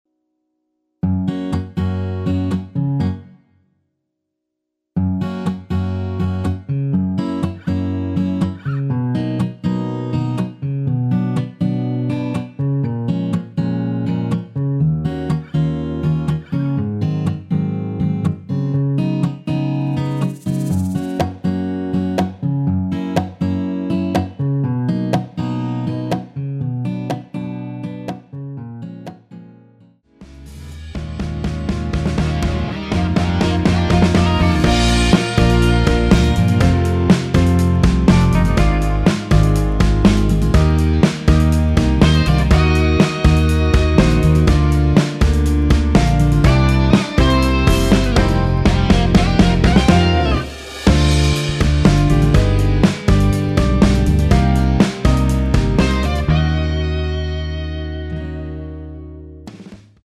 Gb
◈ 곡명 옆 (-1)은 반음 내림, (+1)은 반음 올림 입니다.
앞부분30초, 뒷부분30초씩 편집해서 올려 드리고 있습니다.